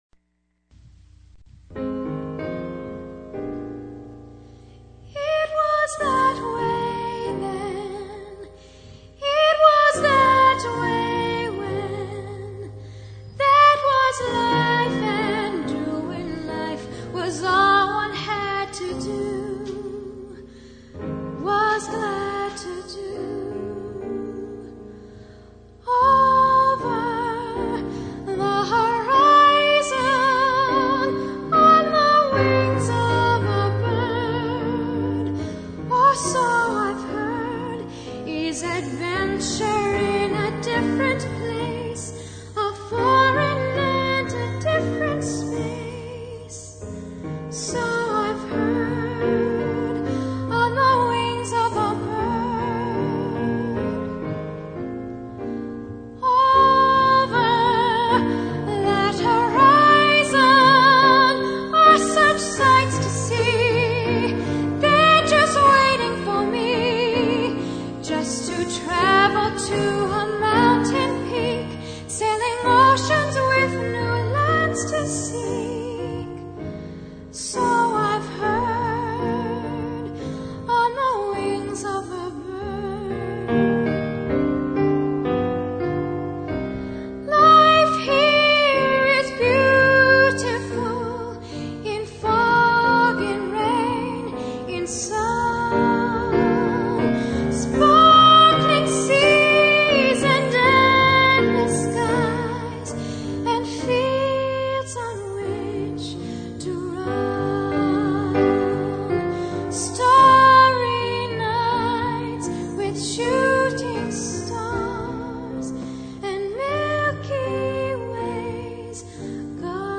Here you can hear samples from both the original sample recording and from the premier perrformance at the Waldo Theater.
THE DEMO: